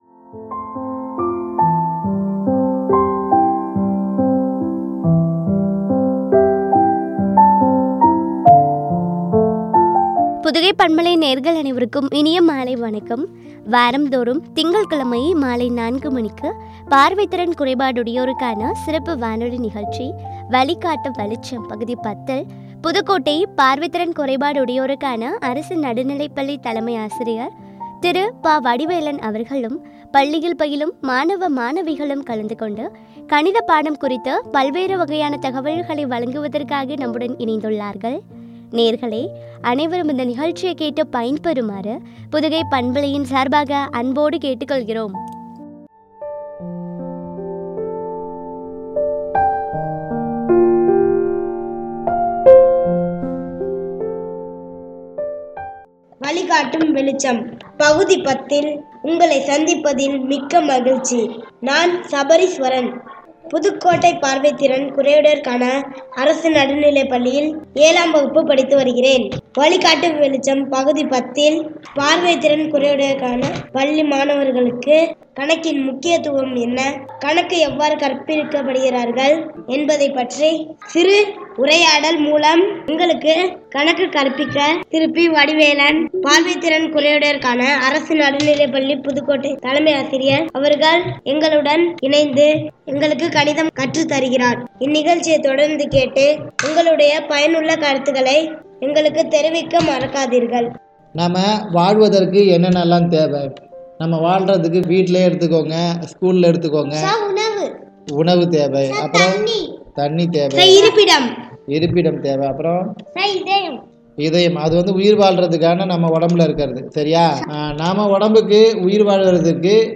பார்வை திறன் குறையுடையோருக்கான சிறப்பு வானொலி நிகழ்ச்சி